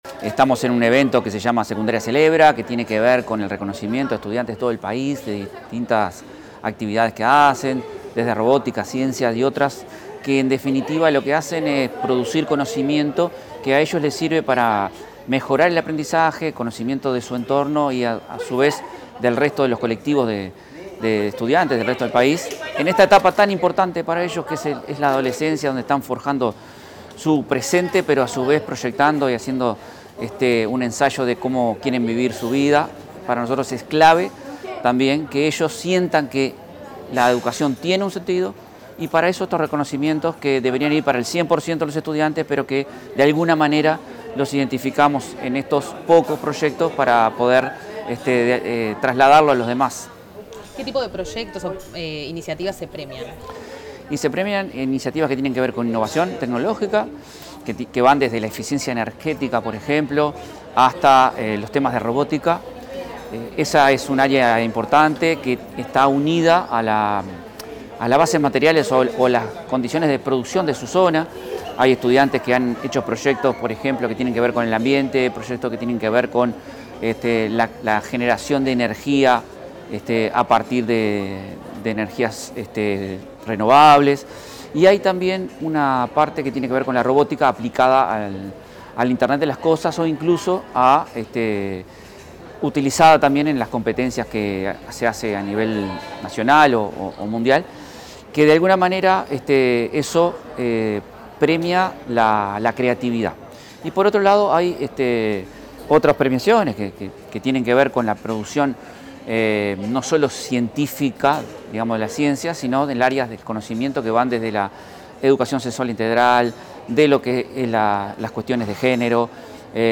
Declaraciones del director de Secundaria, Manuel Oroño